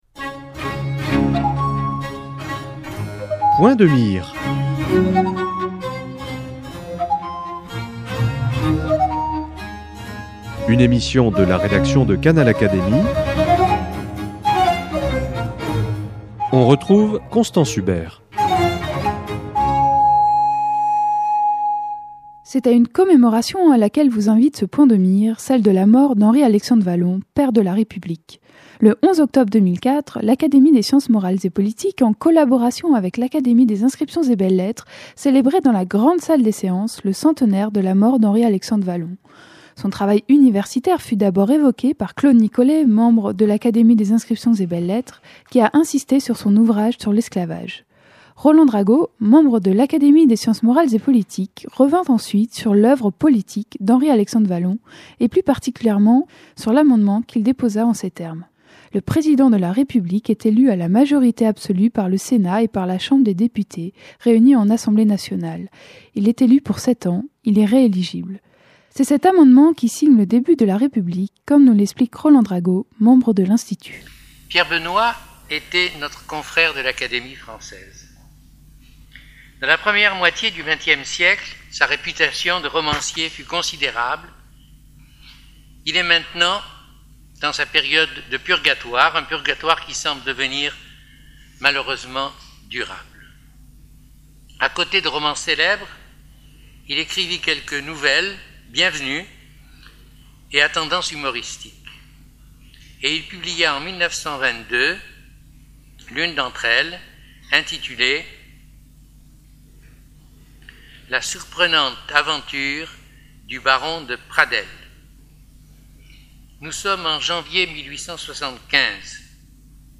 Pour commémorer le centenaire de la mort d'Henri-Alexandre Wallon, les Académies des inscriptions et belles-lettres et des sciences morales et politiques lui ont rendu un commun hommage le lundi 4 octobre.